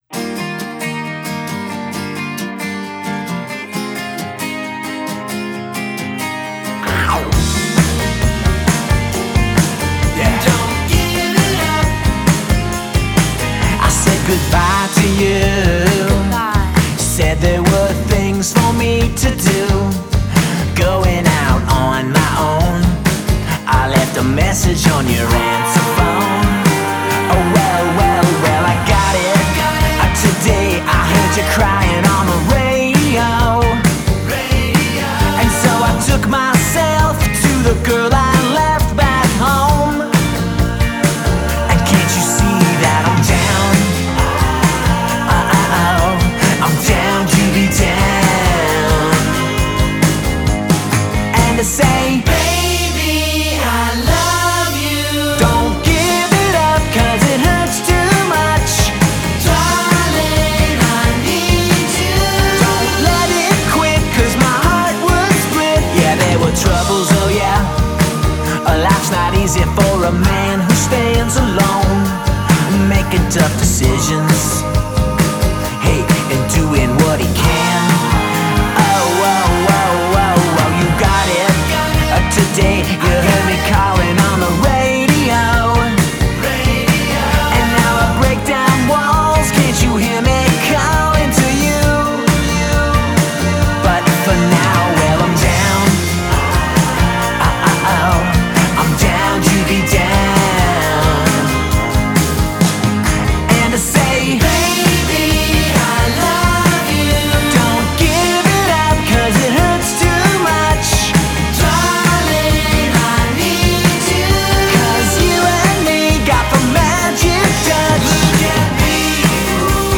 super sweet Partridge Family poprock hookiness
the 1970s AM melody-to-the front pop sound